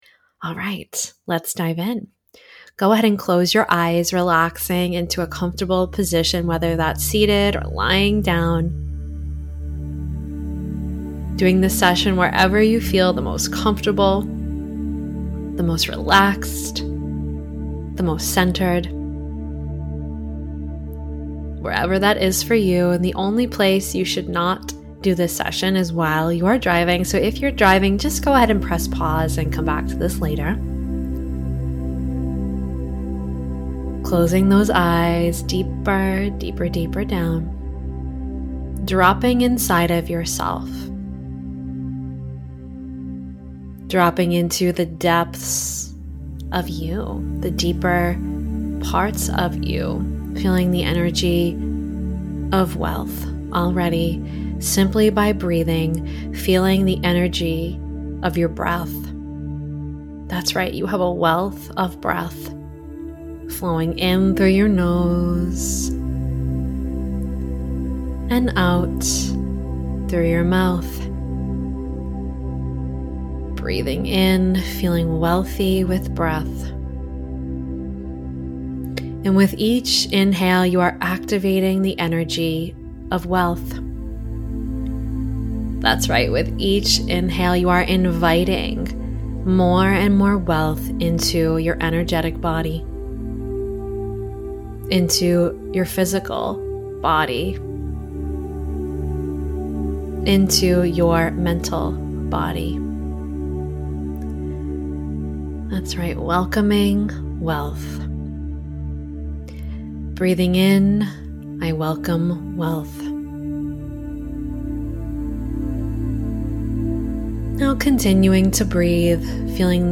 Wealth Activation Hypnosis Audio